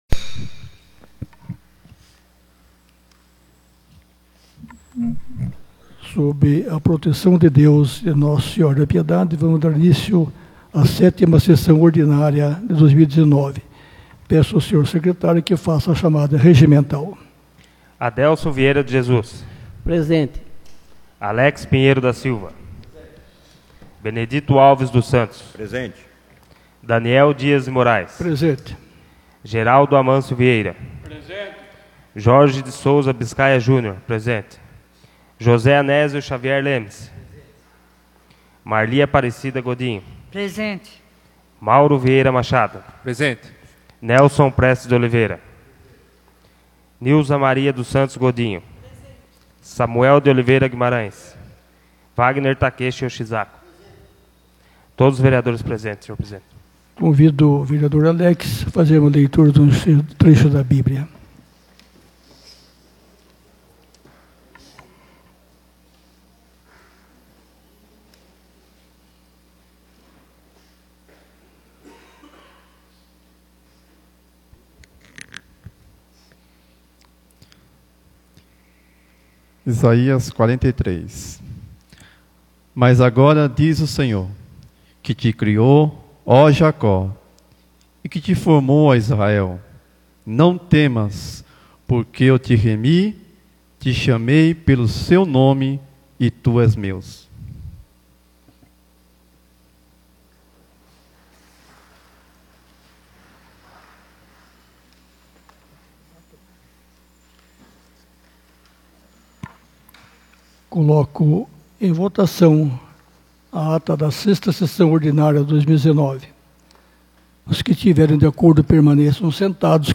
7ª Sessão Ordinária de 2019 — Câmara Municipal de Piedade